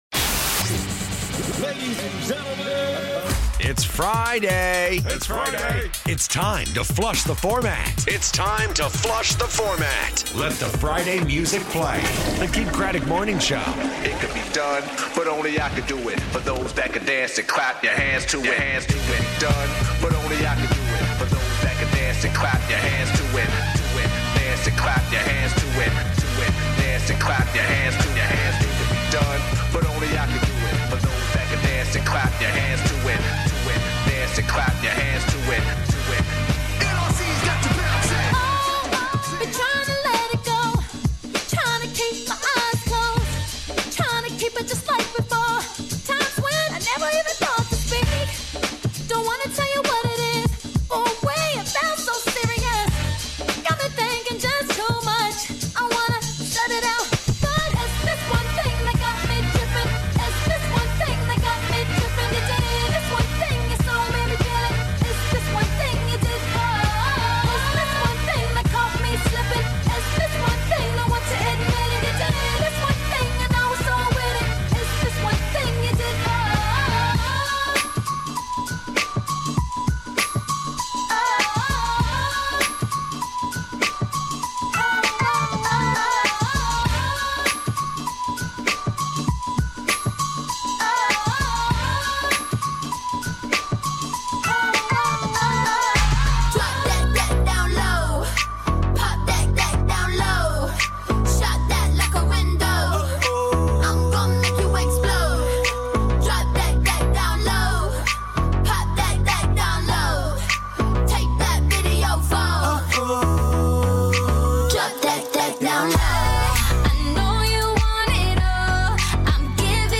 MixShow